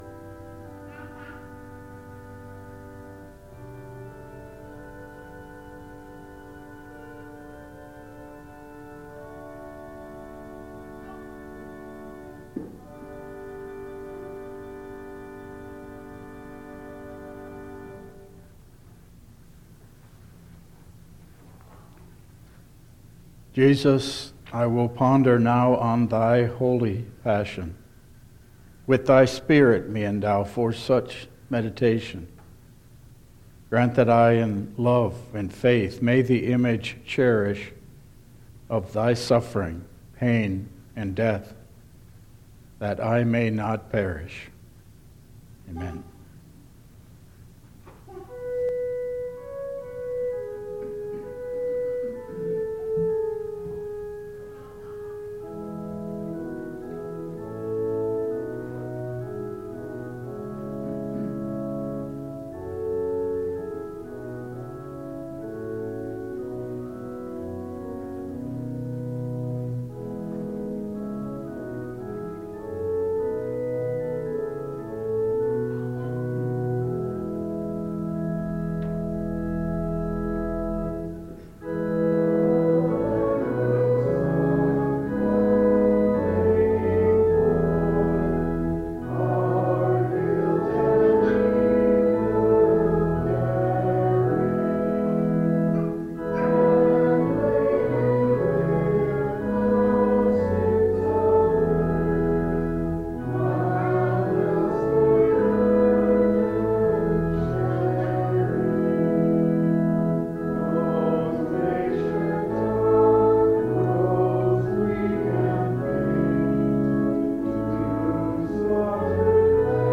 Service Type: Lenten Service